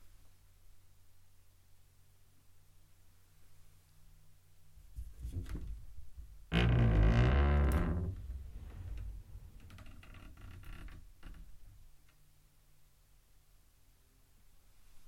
Close/Right wardrobe Door
Duration - 15 s Environment - Bedroom, absorption with bed linen and other furnishings. Description - Wooden, creaks, shuts.